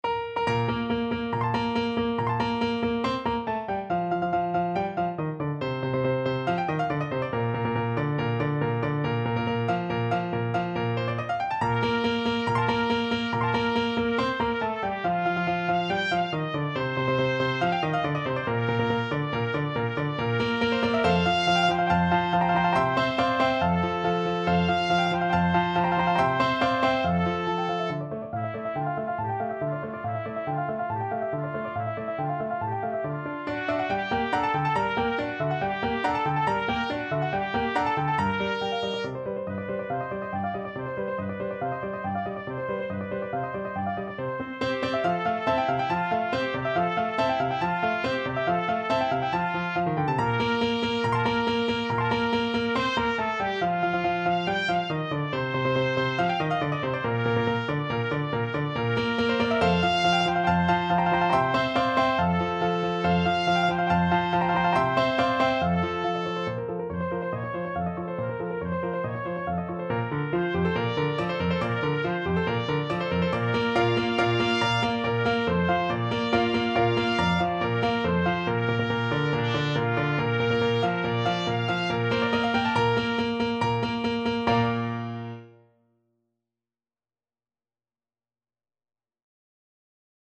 Classical Mozart, Wolfgang Amadeus Bassa Selim lebe lange from Die Entfuhrung aus dem Serail, K.384 Trumpet version
Trumpet
Bb major (Sounding Pitch) C major (Trumpet in Bb) (View more Bb major Music for Trumpet )
~ = 140 Allegro vivace (View more music marked Allegro)
2/4 (View more 2/4 Music)
Classical (View more Classical Trumpet Music)